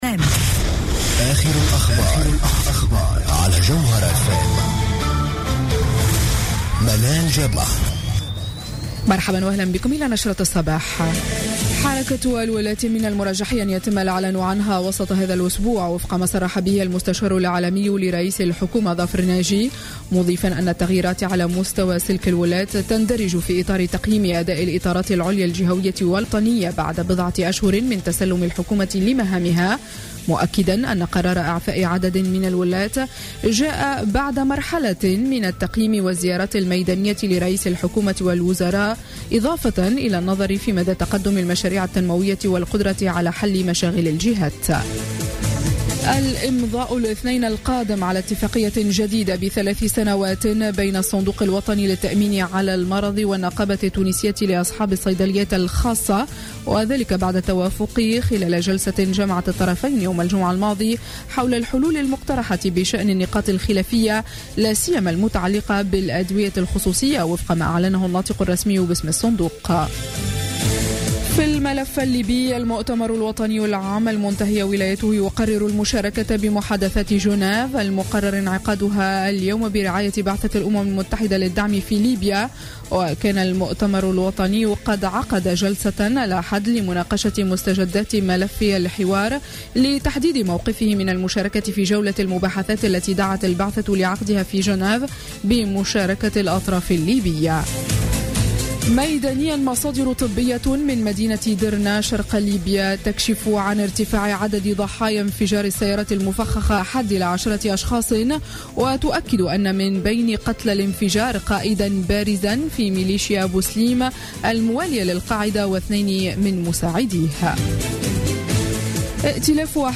نشرة أخبار السابعة صباحا ليوم الثلاثاء 11 أوت 2015